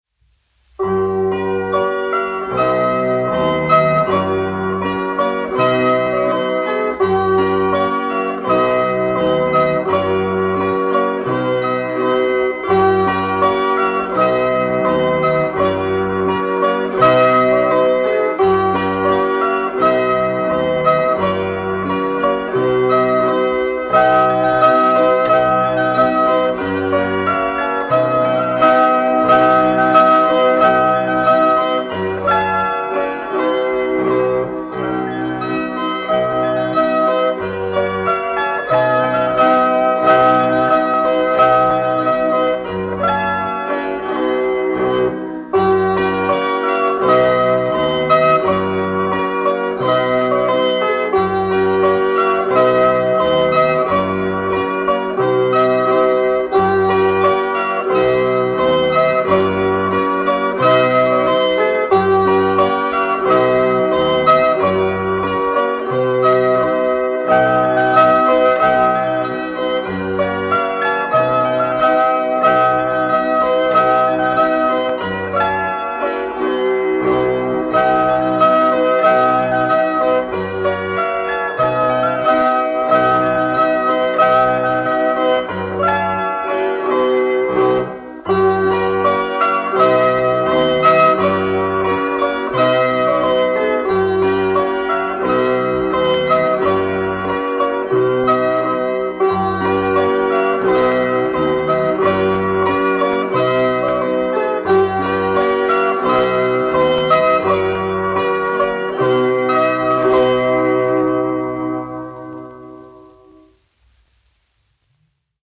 Krusta kazaks : latviešu tautas deja
1 skpl. : analogs, 78 apgr/min, mono ; 25 cm
Latviešu tautas dejas
Kokles mūzika
Latvijas vēsturiskie šellaka skaņuplašu ieraksti (Kolekcija)